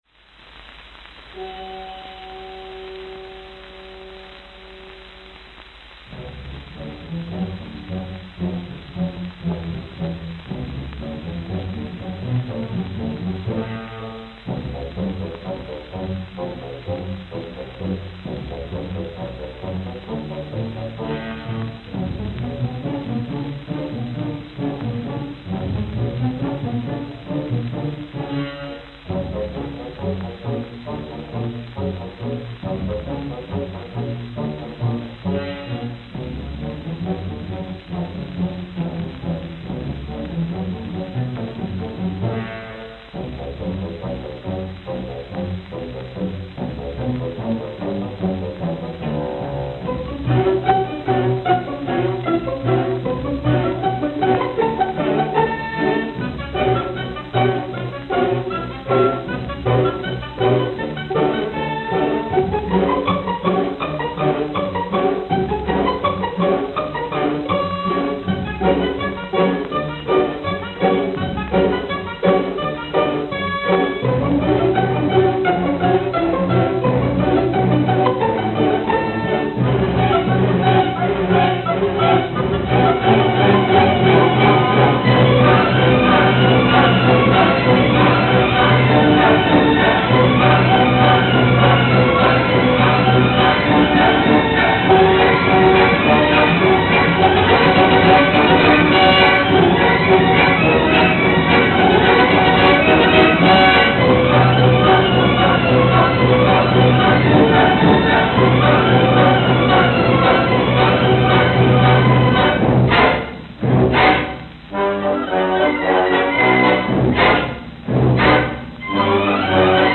Church Building.